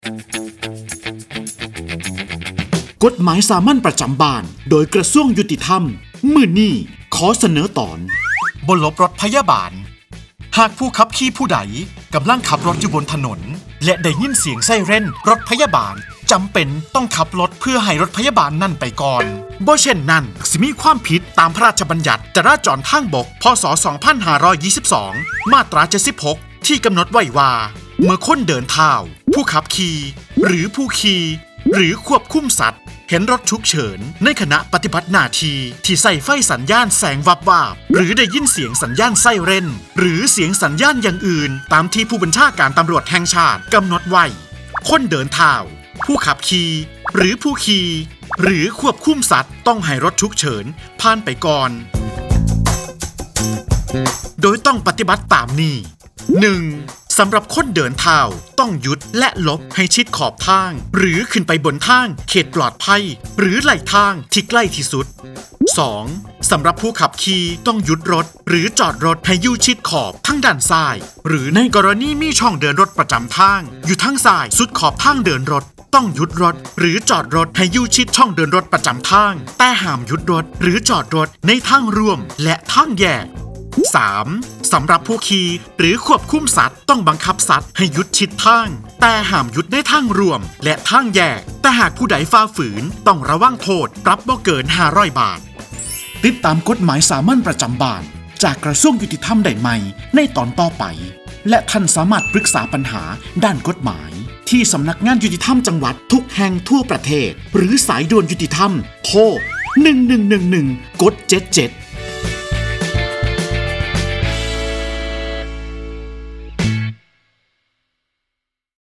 กฎหมายสามัญประจำบ้าน ฉบับภาษาท้องถิ่น ภาคอีสาน ตอนไม่หลบรถพยาบาล
ลักษณะของสื่อ :   บรรยาย, คลิปเสียง